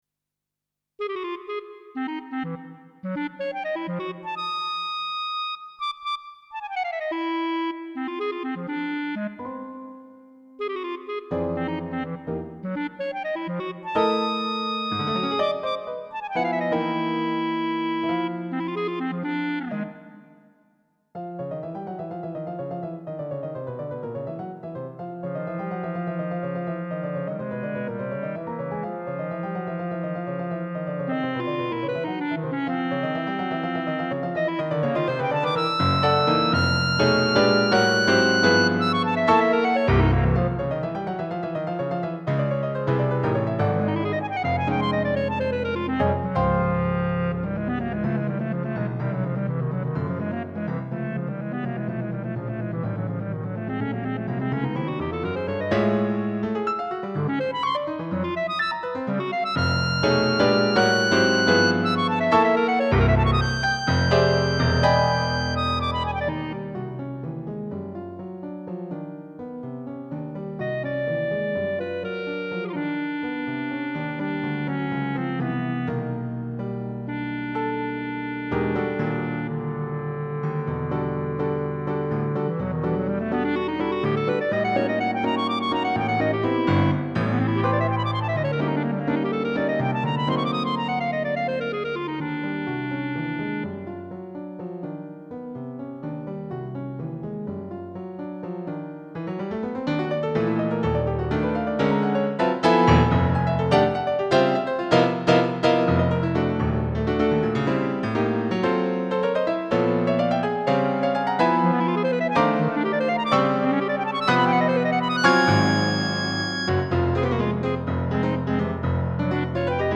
Midi version for demo only